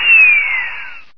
spindown.ogg